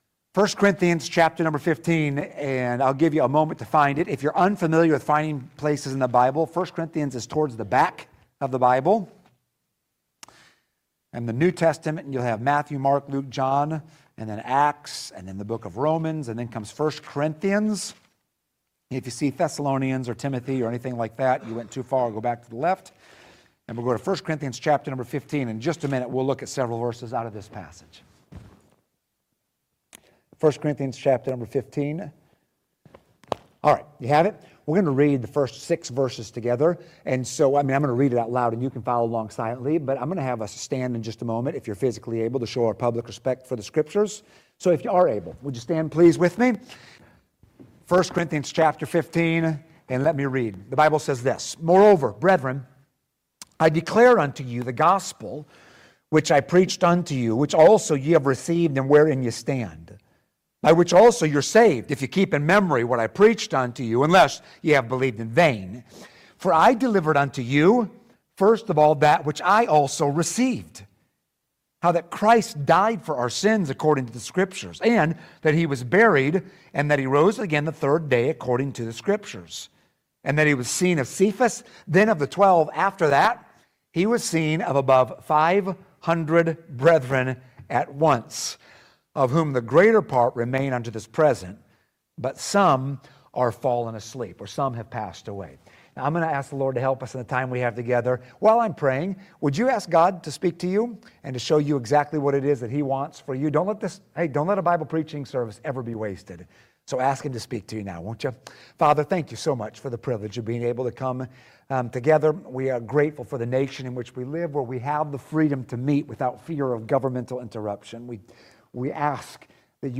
1 Cor. 15:3-4 Service Type: Sunday AM « Let the Redeemed of the Lord Say So!